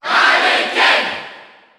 Category: Crowd cheers (SSBU) You cannot overwrite this file.
Ken_Cheer_French_SSBU.ogg.mp3